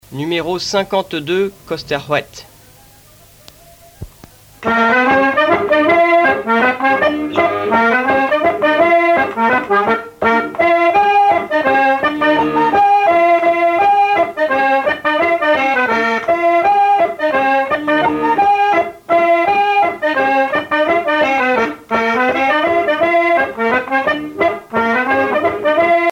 Séglien
danse : kost ar c'hoad
Pièce musicale éditée